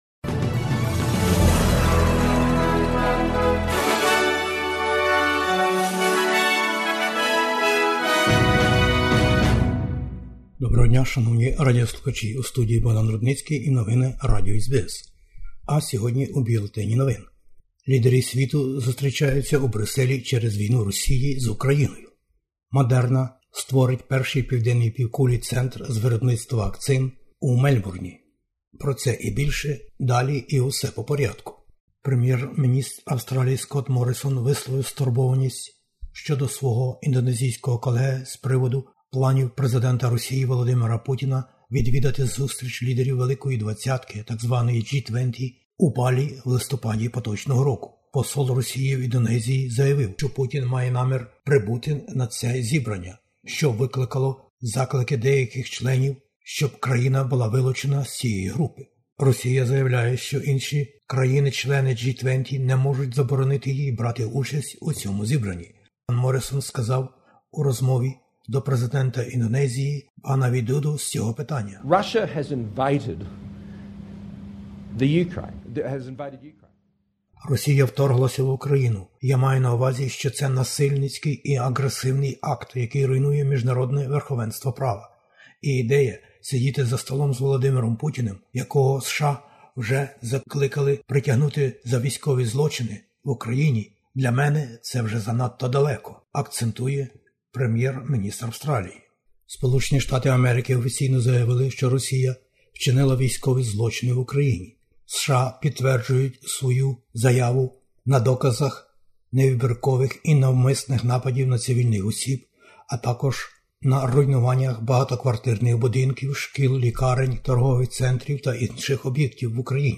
Бюлетень новин SBS українською. Прем'єр-міністр Австралії висловив здивування і незгоду через можливу участь російського президента у зібранні країн G20 у Балі.